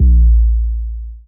Kick SwaggedOut 8.wav